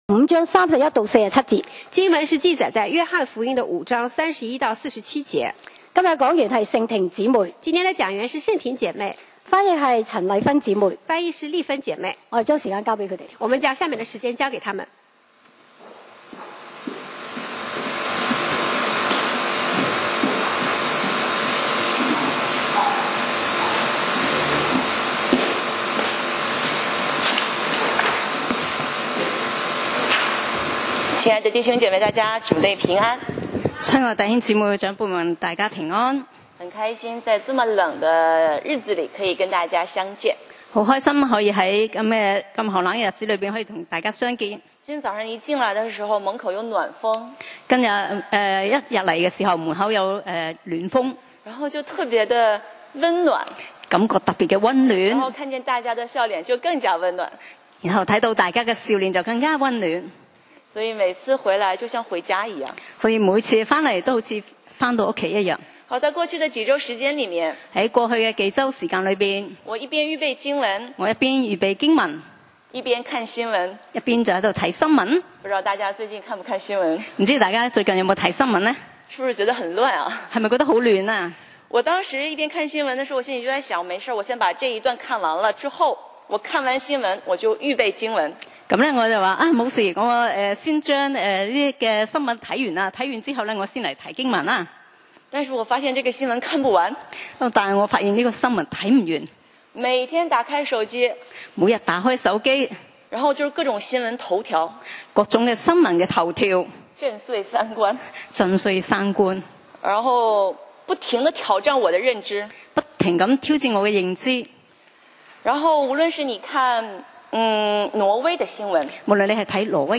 講道 Sermon 題目 Topic：看見與求證 經文 Verses：约翰福音 5:31-47. 31 我若為自己作見證，我的見證就不真。